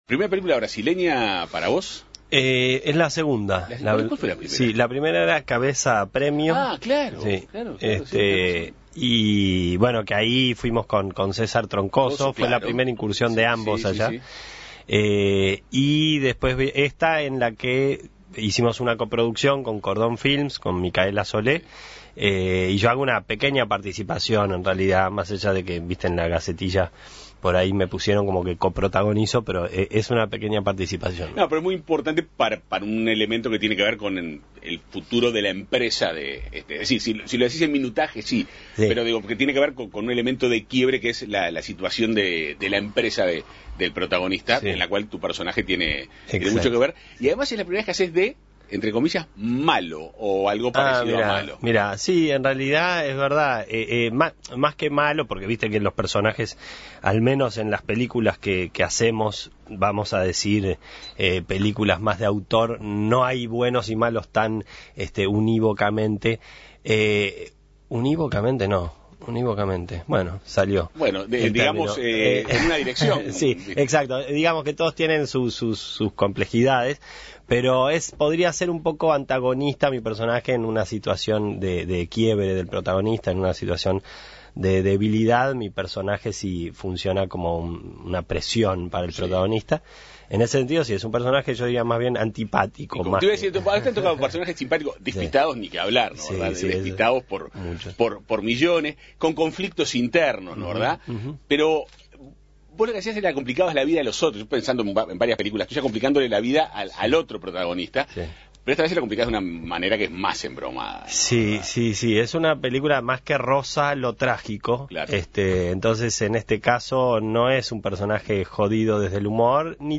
El próximo jueves 3 de julio se estrenará en la sala Lumiere del Cine Universitario la película "Entre Valles", una coproducción de Brasil, Uruguay y Alemania dirigida por Philippe Barcinski y protagonizada por el brasileño Ángelo Antonio y el uruguayo Daniel Hendler. Hoy en 810 Vivo Avances, tendencias y actualidad recibimos en estudio a Daniel Hendler.